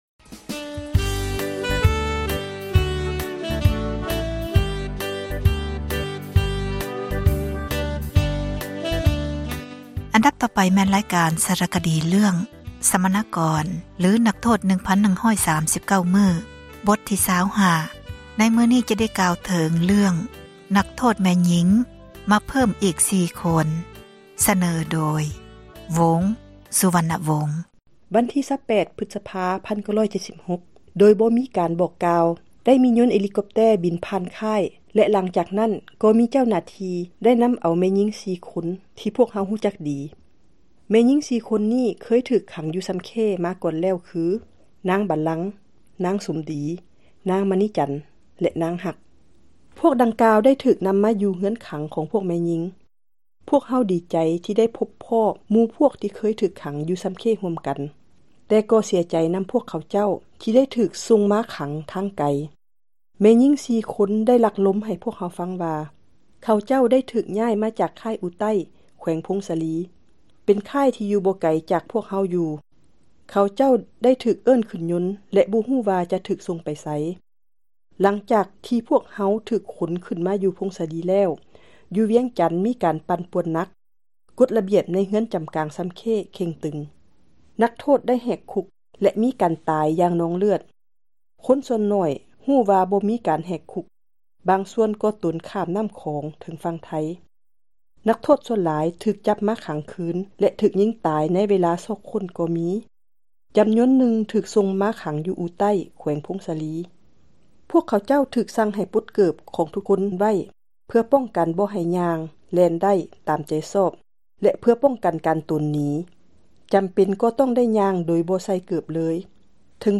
ສາຣະຄະດີ ເຣື້ອງ ສັມມະນາກອນ ຫຼື ນັກໂທດ 1,139 ມື້. ບົດ ທີ 25, ໃນມື້ນີ້ ຈະກ່າວເຖິງ ນັກໂທດ ແມ່ຍິງ ເພີ້ມອີກ 4 ຄົນ. ສເນີໂດຍ